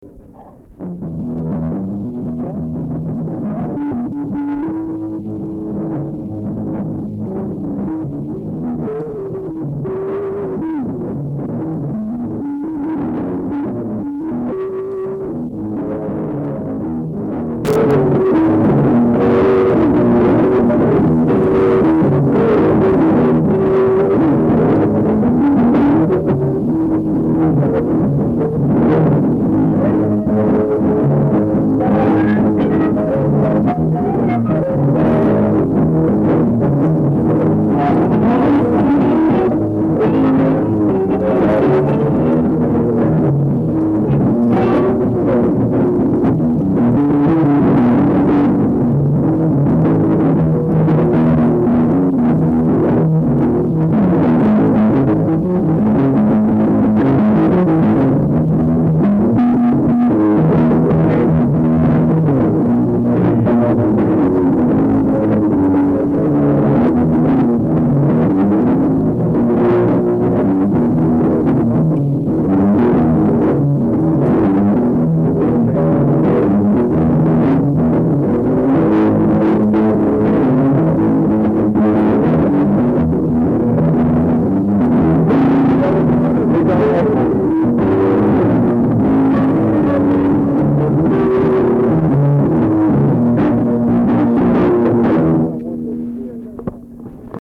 This was an outdoor concert held on a Sunday afternoon in the summer of 1972 in Harrison Smith Park in Upper Sandusky, Ohio.
bass
drums
lead guitar
keyboards
lead vocals.
Disclaimer: The audio in the clips is of extremely poor quality! Plus my friends and I were talking throughout.
"break song" (instrumental blues played just before a break)
BreakSong(bluesJam).mp3